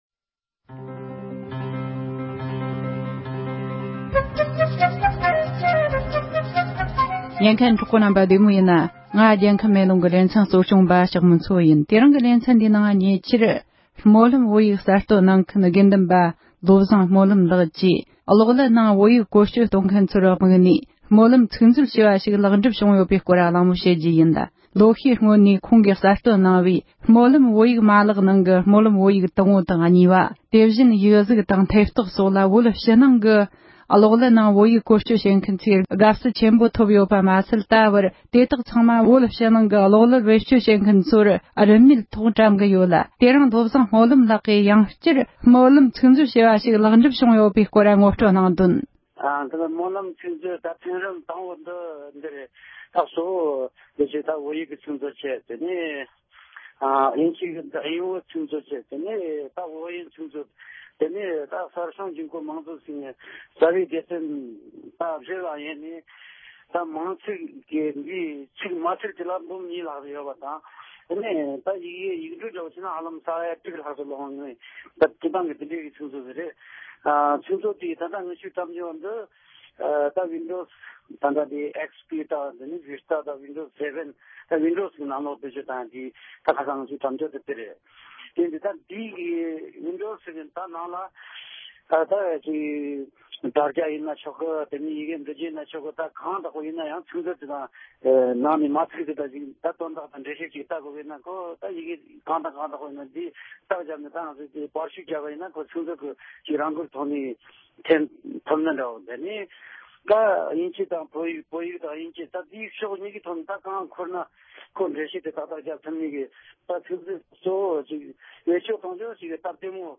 སྨོན་ལམ་ཚིག་མཛོད་ལེཊ་པར་འགྲུབ་ནས་བོད་མིའི་སྤྱི་ཚོཊ་ནང་རིན་མེད་འགྲེམས་སྤེལ་གནང་ཡོད་པའི་སྐོར་གླེང་མོལ།